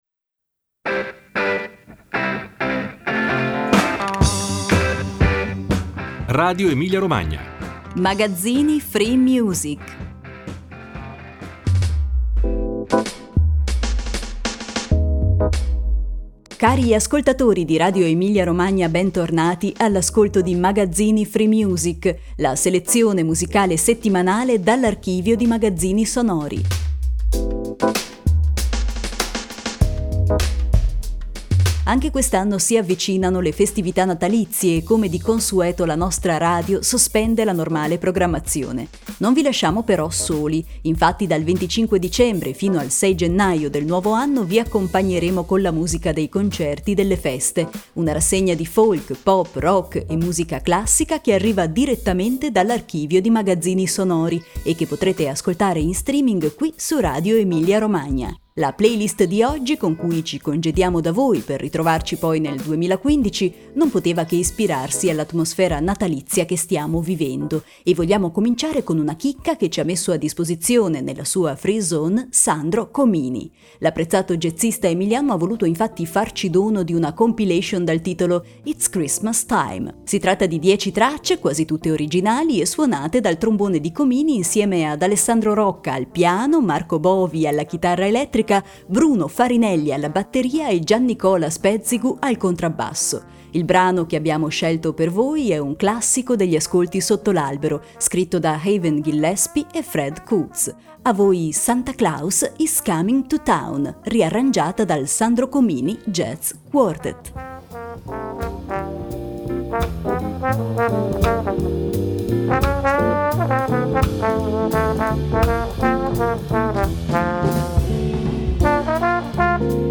Cari ascoltatori di RadioEmiliaRomagna, bentornati all'ascolto di Magazzini FreeMusic, la selezione musicale settimanale dall'archivio di Magazzini Sonori.
Con l'arrivo di Santa Claus in chiave jazz abbiamo aperto la nostra ultima playlist prima della chiusura natalizia della nostra radio.